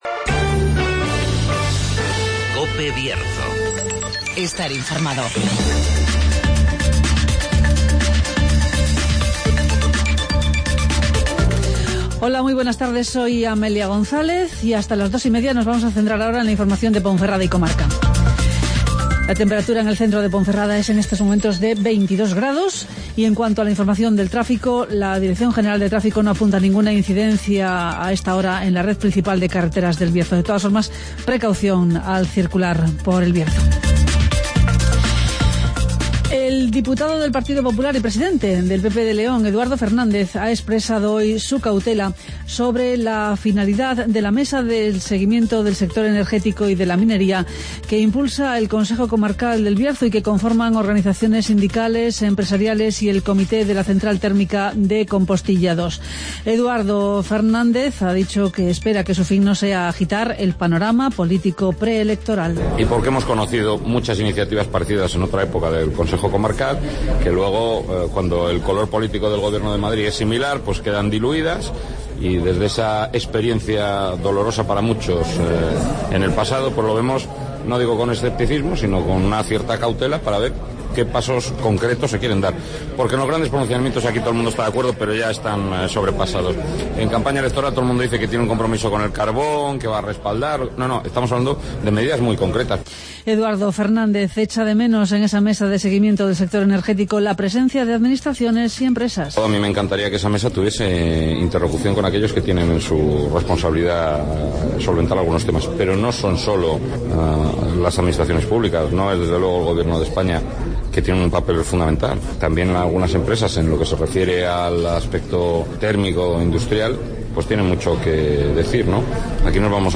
Informativo Mediodía COPE Bierzo 06-11-15